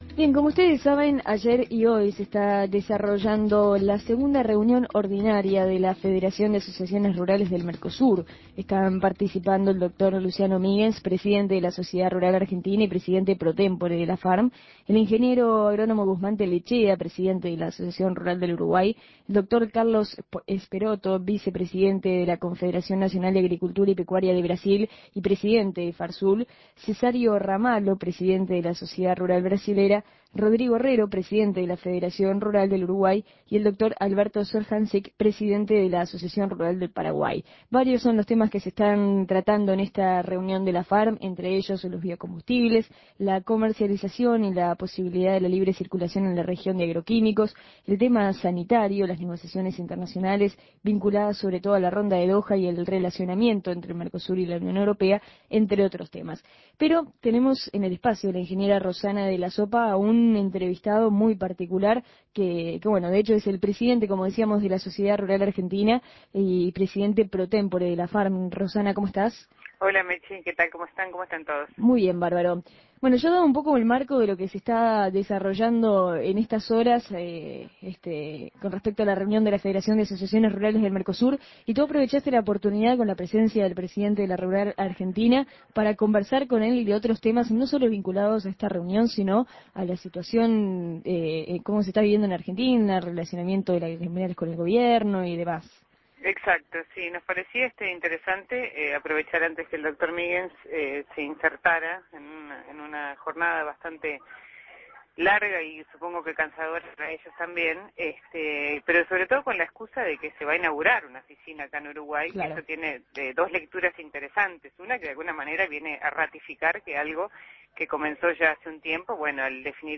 De esto y de la realidad del sector agropecuario argentino en Objetivo Agropecuario dialogamos con el presidente de la Sociedad Argentina, el doctor Luciano Miguens, en oportunidad de la inauguración de la oficina en las instalaciones de IICA Uruguay.